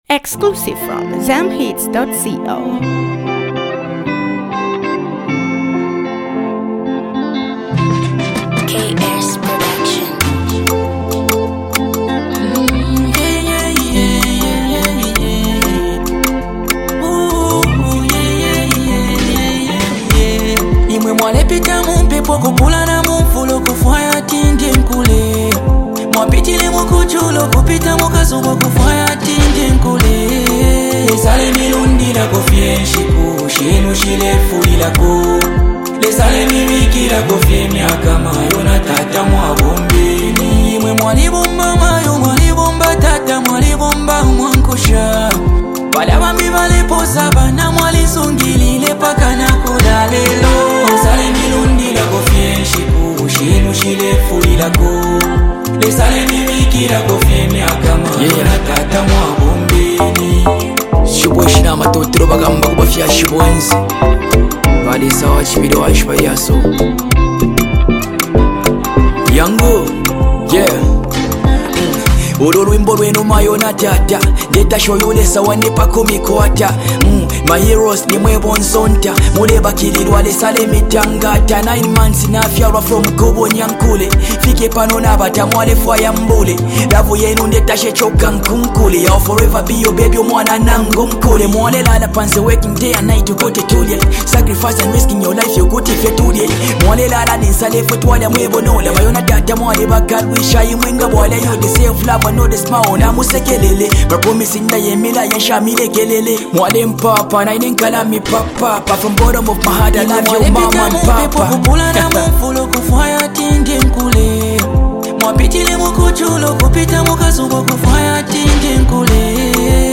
one of Zambia’s most consistent and talented music duos